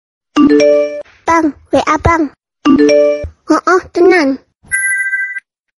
Kategori: Nada dering
Nada dering notifikasi yang lucu ini lagi viral banget di TikTok.